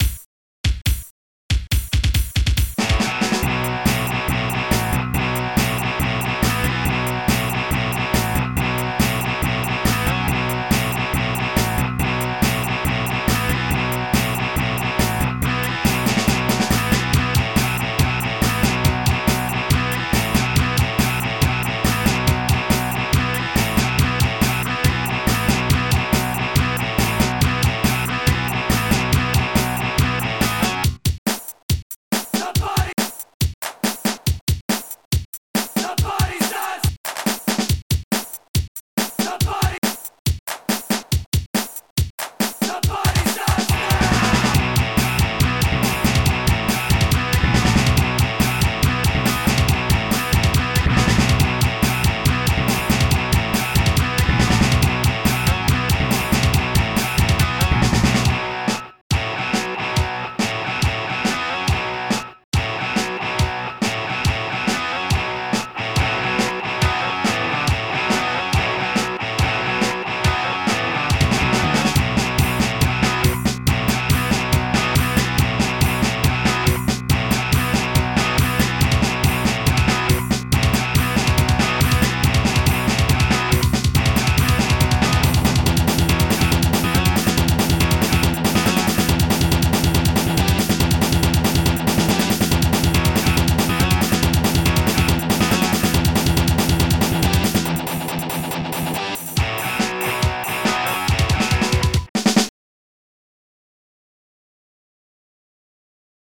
4channel music compo.
All guitar samples
Other samples ripped.